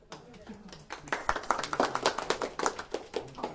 笑声" 集体鼓掌2
描述：鼓掌的人搞笑幽默人类笑话故事